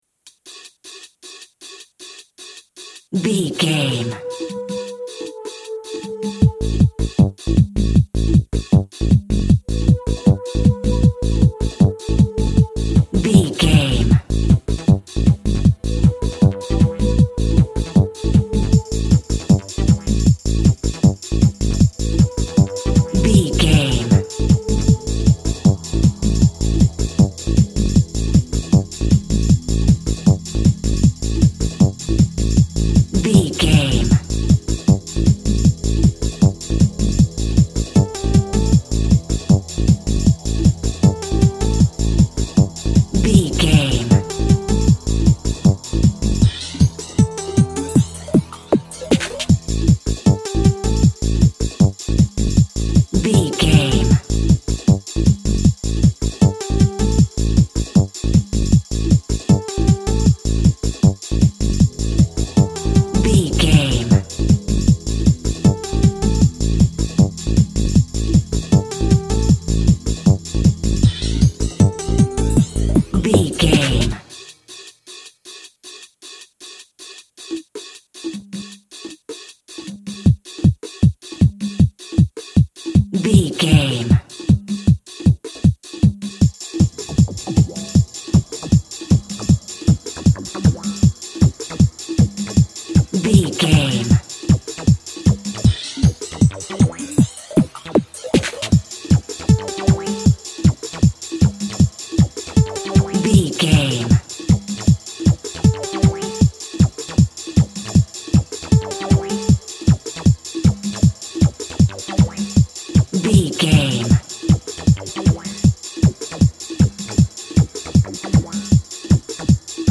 Skateboarding Trance Music.
Aeolian/Minor
Fast
energetic
high tech
futuristic
hypnotic
industrial
drums
bass guitar
synthesiser
drum machine
techno
synth lead
synth bass
Synth pads